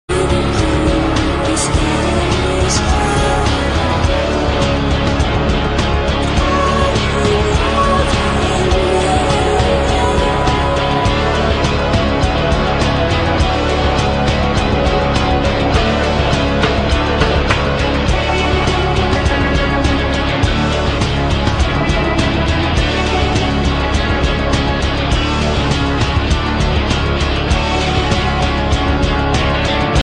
played more explicitly on the darkness and tension